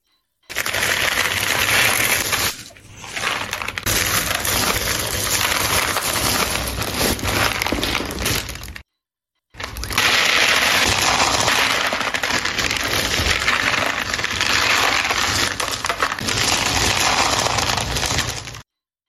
Tiếng Đổ những Đồ vật nhỏ ra khỏi bao
Thể loại: Tiếng động
Description: Cảm giác sống động như thể hàng trăm mảnh vụn, viên bi hay hạt nhỏ đang trượt và va chạm vào nhau. Âm thanh rọc rẹc, lạo xạo, lách tách, sột soạt hòa quyện, gợi hình ảnh bàn tay nghiêng chiếc túi, để từng món đồ rơi lăn lóc xuống mặt phẳng... tái hiện cảm giác chuyển động, đổ trút hay xáo trộn của những vật thể nhỏ li ti.
tieng-do-nhung-do-vat-nho-ra-khoi-bao-www_tiengdong_com.mp3